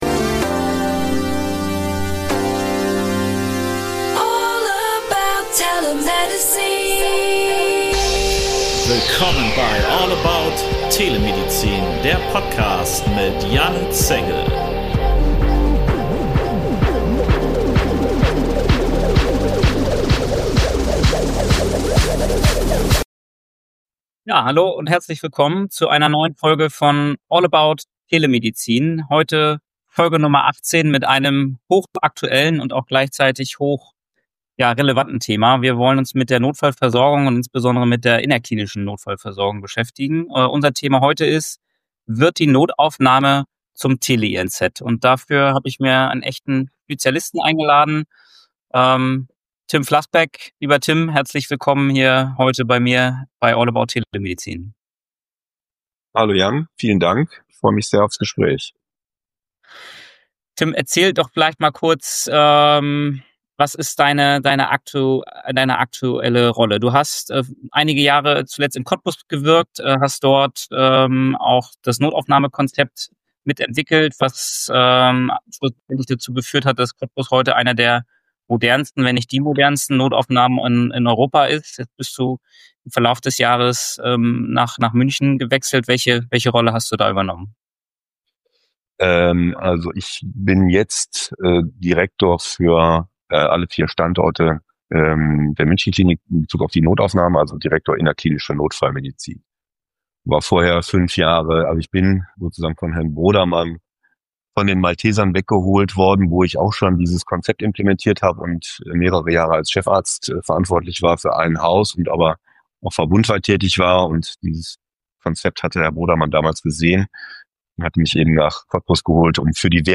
Ein Klartext-Gespräch über Versorgungsrealität, Systemversagen – und echte Lösungen.